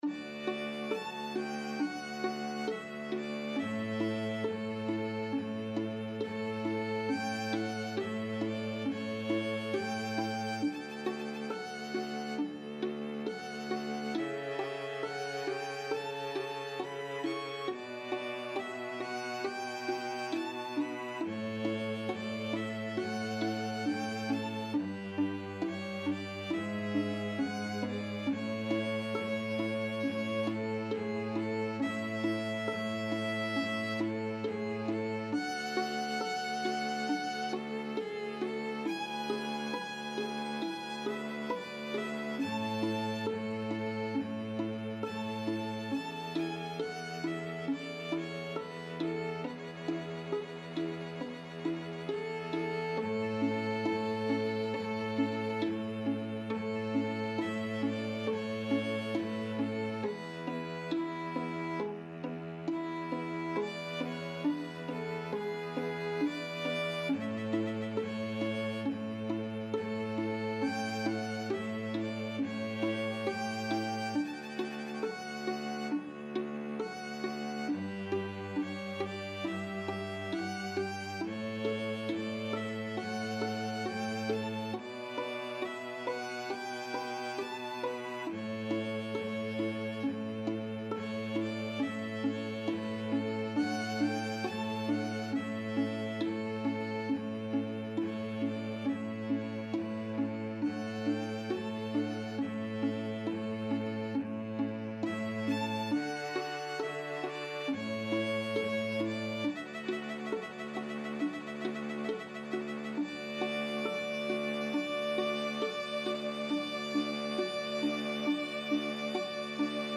Violin 1Violin 2ViolaCello
4/4 (View more 4/4 Music)
= 34 Grave
Classical (View more Classical String Quartet Music)